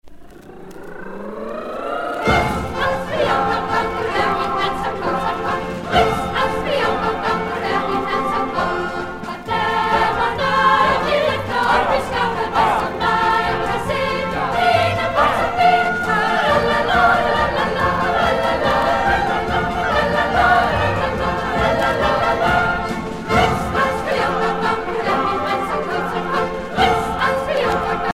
Danske folkesange
Pièce musicale éditée